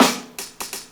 Snare 8.wav